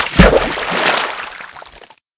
wood_click.ogg